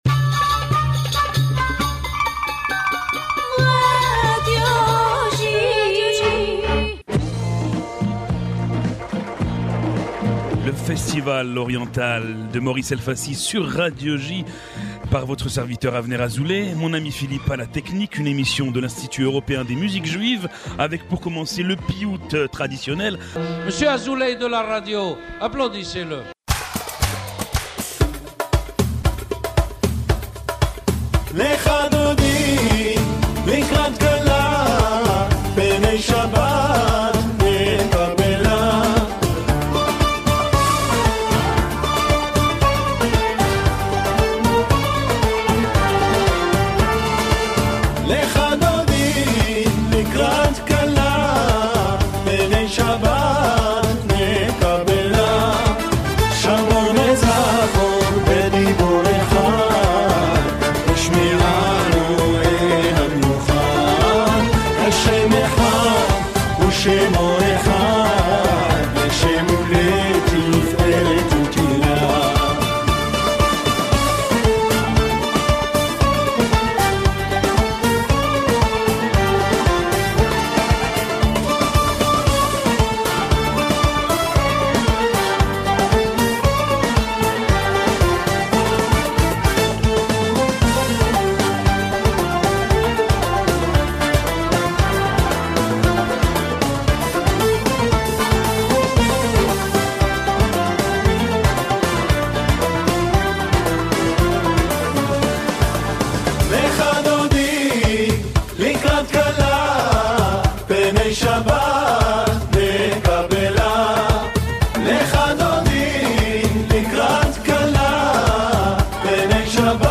« Le festival oriental » est une émission de l’Institut Européen des Musiques Juives entièrement dédiée à la musique orientale.